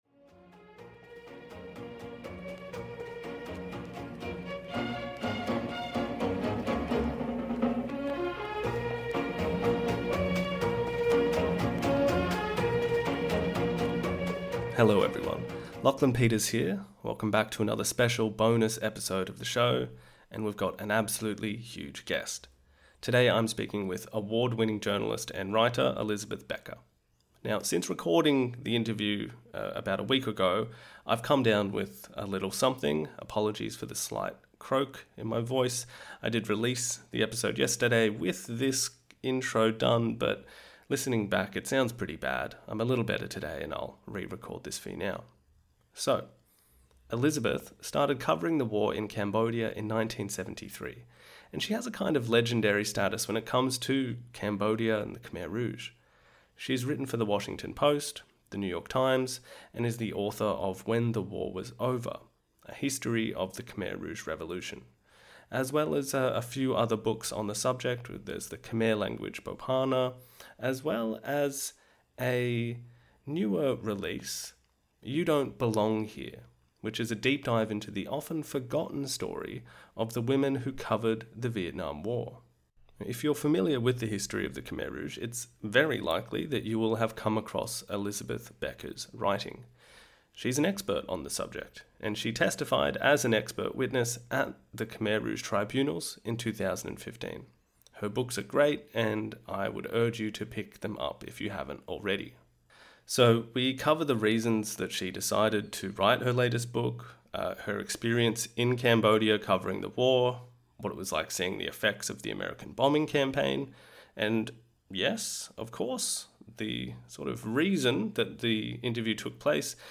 Interview: "Rendez-vous avec Pol Pot" with Elizabeth Becker